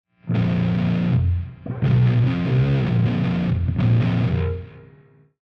Also – while I could have edited the clips a little tighter – the hum from the CRT is present in the clips so this should be a realistic testing environment of what some one would get just pluging this into their laptop.
Here is the sound of a dirge type of riff played with the neck pickup.
schecter-omen-chunk-neck.mp3